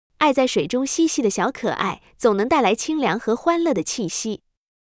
water.mp3